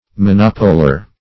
Monopoler \Mo*nop"o*ler\, n.
monopoler.mp3